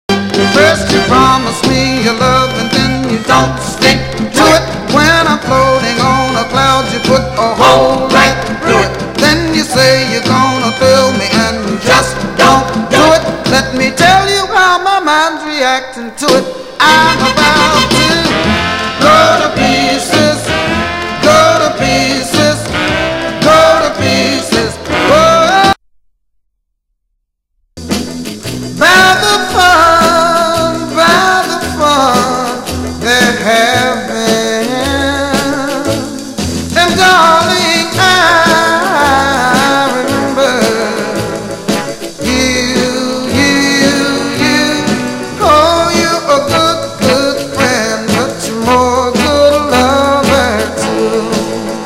ハーモニカを導入しアーシーな仕上がりとなっているA面にニューオリンズ音楽好きにもアピールする三連のB面。
(税込￥4180)   SOUL/R&B